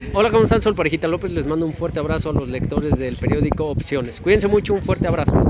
Con el objetivo de recaudar fondos para el Sistema Nacional para el Desarrollo Integral de la Familia (DIF) del municipio de Tepeji del Río, la fundación Cambiando Familias y Viviendo Mejor, se llevó a cabo, el pasado viernes 06 de marzo, el segundo torneo de golf celebridades y estrellas 2020 en el Amanali Country Club & Nautic de dicho municipio hidalguense.
Saludo del Parejita López